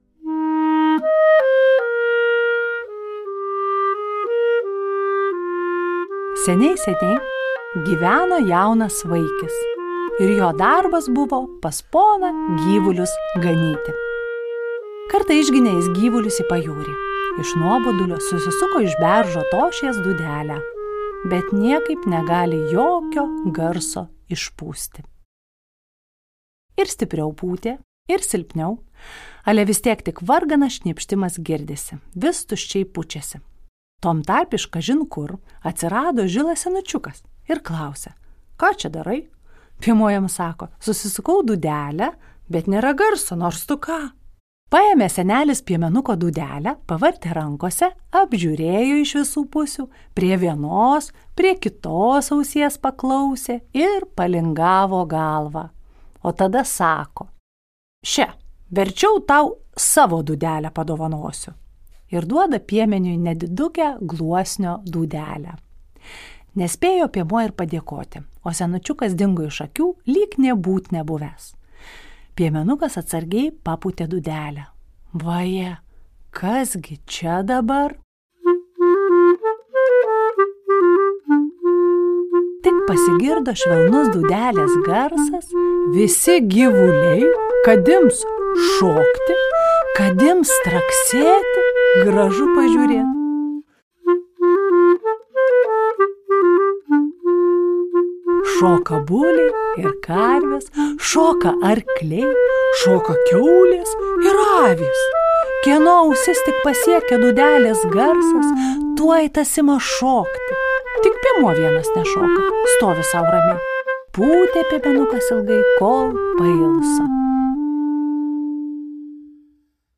Tinklalaidės tema – J. S. Bacho „Goldbergo variacija“ nr. 10, atliekama pianisto Kimiko Ishizaka Tinklalaidė įrašyta Lietuvos nacionalinės Martyno Mažvydo bibliotekos garso įrašų studijoje